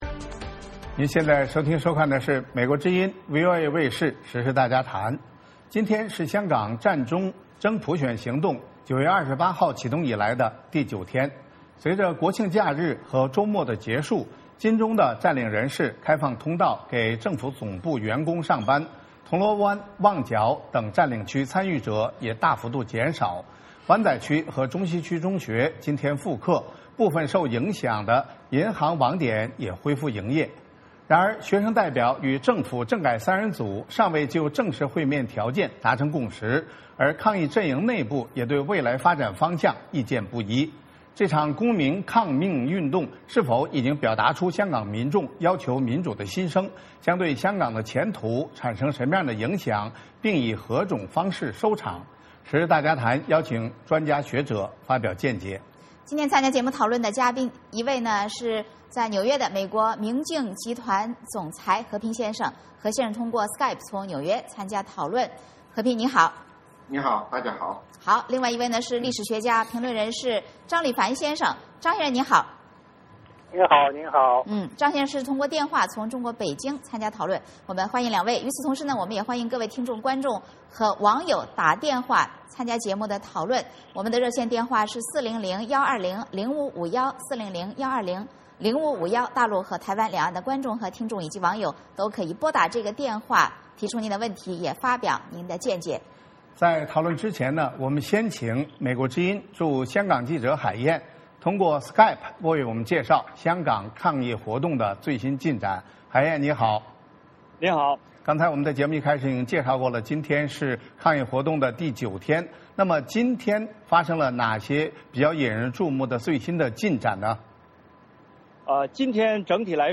时事大家谈邀请专家学者发表意见。